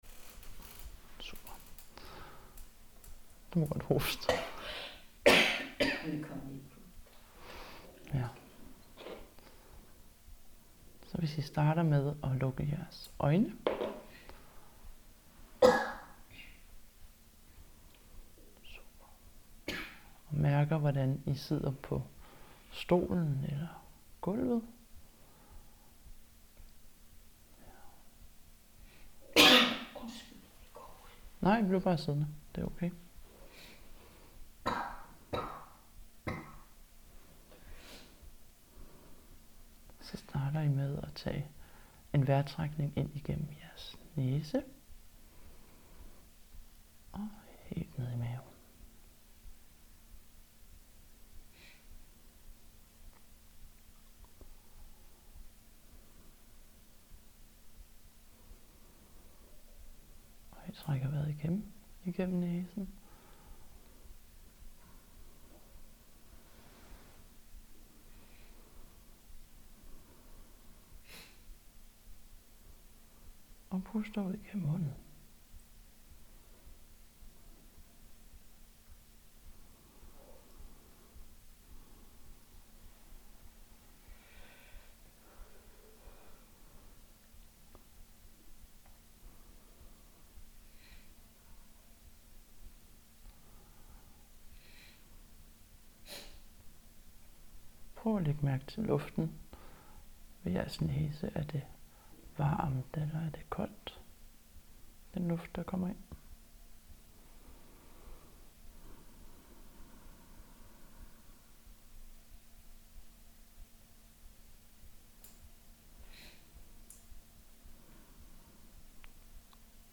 Meditation på vejertrækningen